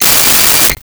Cell Phone Ring 10
Cell Phone Ring 10.wav